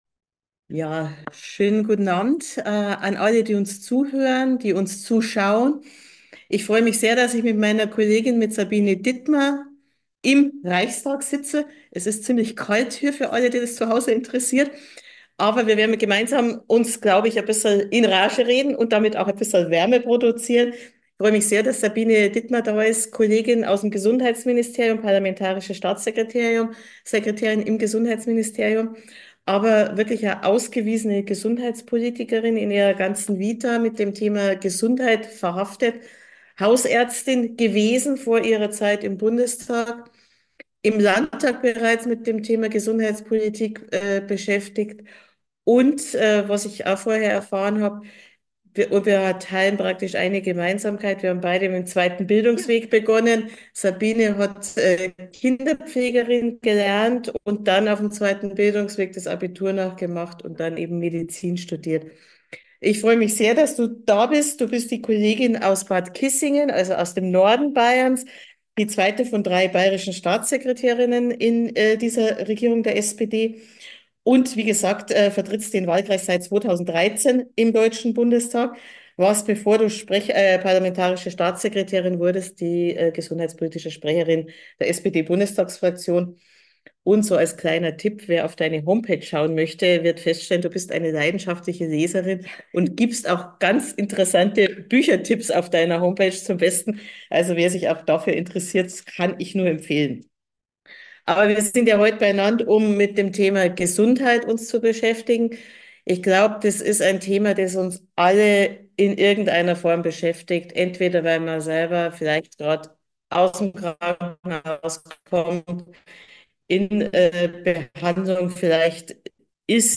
Podcast: Im Gespräch mit Sabine Dittmar, MdB